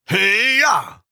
Effort Sounds
20. Effort Grunt (Male).wav